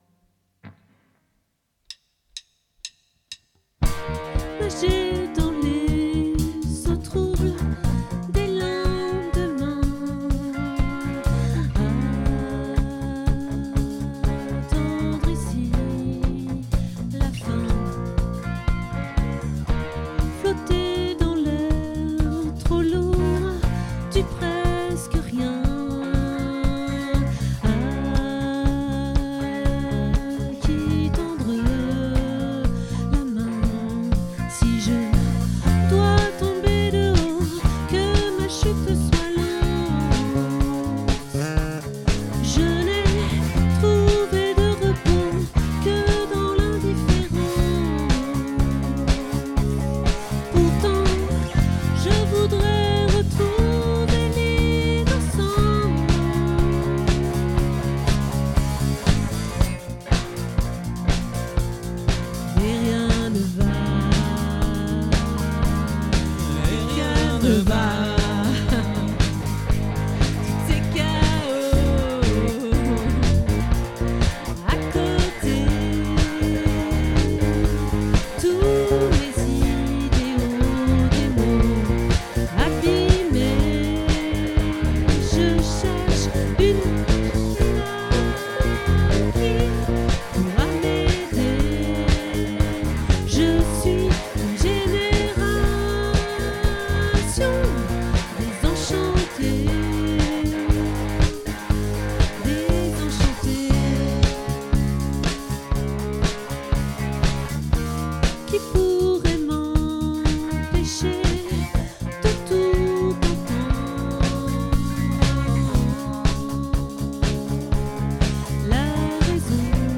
🏠 Accueil Repetitions Records_2025_09_15